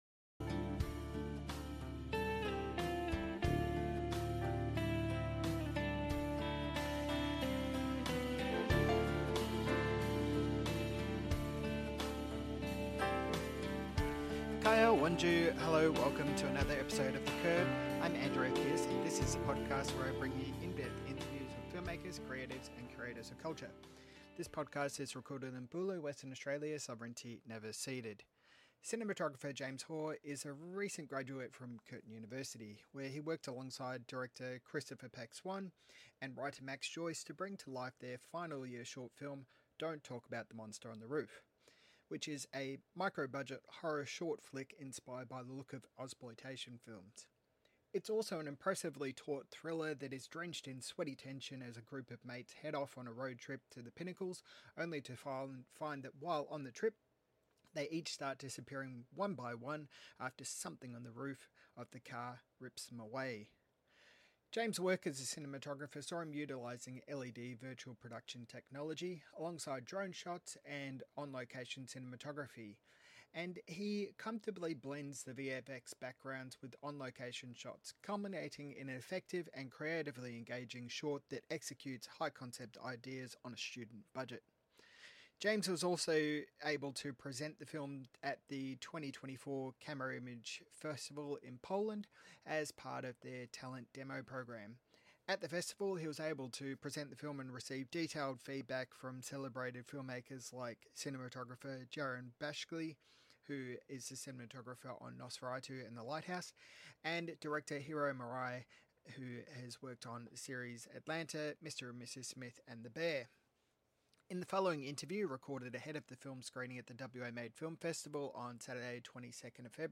WA Made Film Festival Interview